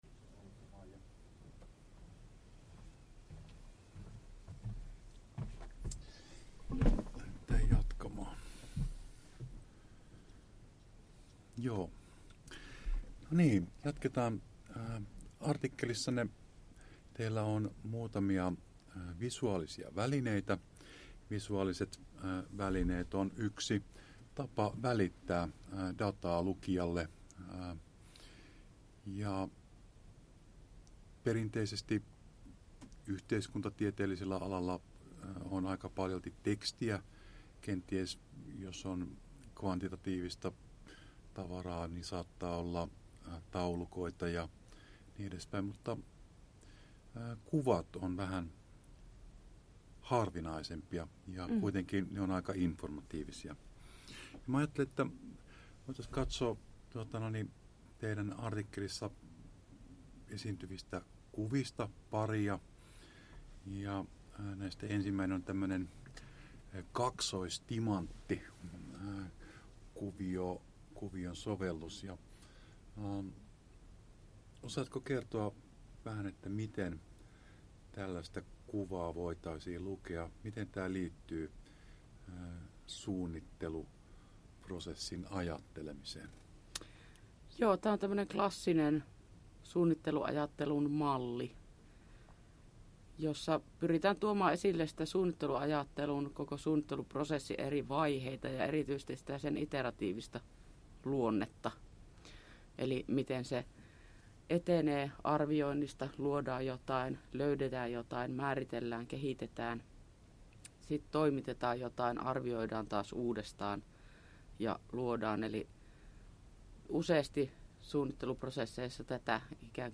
tutkijahaastattelu osa 2